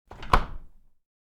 Fridge Door Close Wav Sound Effect #2
Description: The sound of closing a fridge door
Properties: 48.000 kHz 16-bit Stereo
Keywords: fridge, refrigerator, freezer, door, close, closed, closing
fridge-door-close-preview-2.mp3